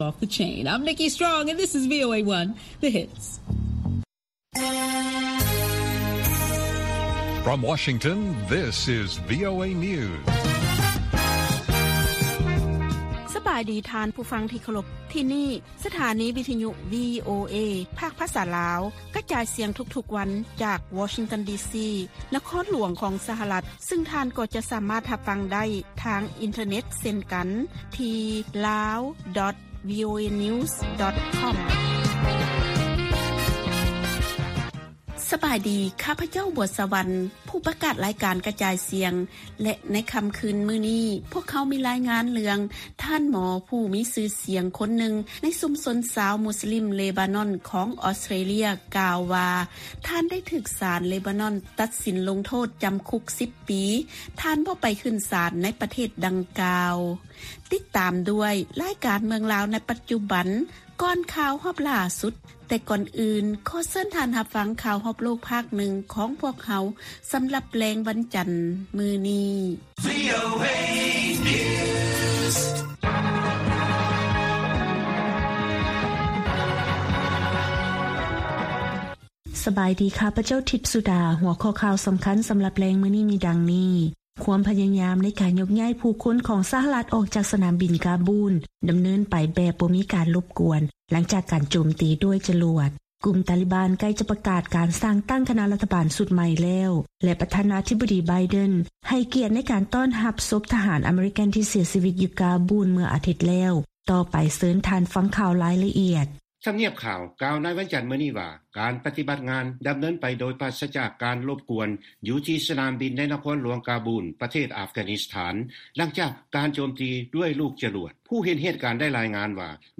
ວີໂອເອພາກພາສາລາວ ກະຈາຍສຽງທຸກໆວັນ. ຫົວຂໍ້ຂ່າວສໍາຄັນໃນມື້ນີ້ມີ: 1) ເຈົ້າໜ້າທີ່ລາວ ບໍ່ສາມາດຄວບຄຸມ ລາຄາສິນຄ້າໄດ້ເພາະສ່ວນໃຫຍ່ເປັນສິນຄ້ານຳເຂົ້າ.